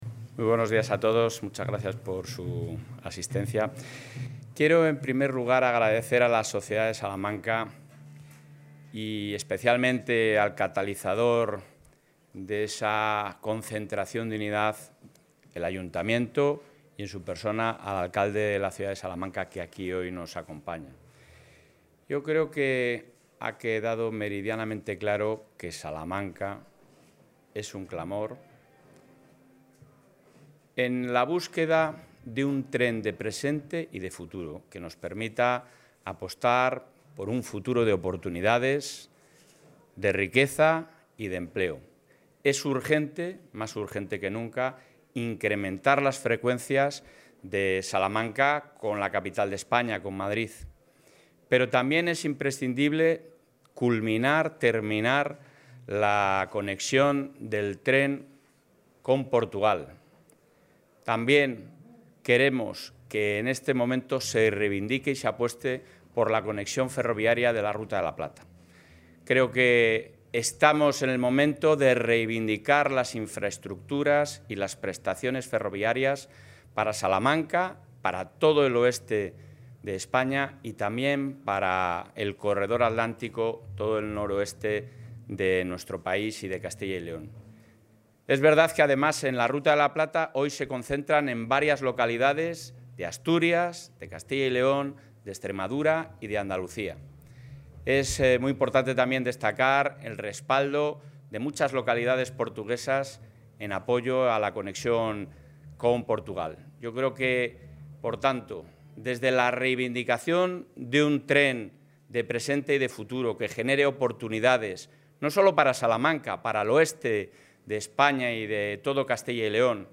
Intervención del presidente de la Junta.
El presidente de la Junta de Castilla y León, Alfonso Fernández Mañueco, ha participado hoy en la concentración en defensa de las comunicaciones ferroviarias de Salamanca, celebrada en la Plaza Mayor de la capital salmantina.